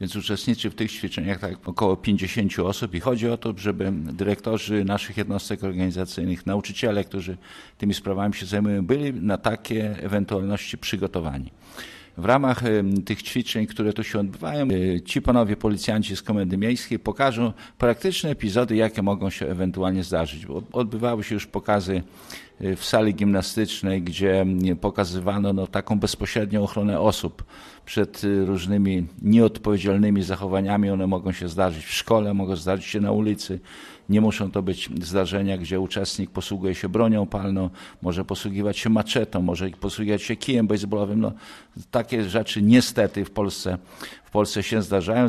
O szczegółach ćwiczeń "Azyl" mówi Starosta Żniński Zbigniew Jaszczuk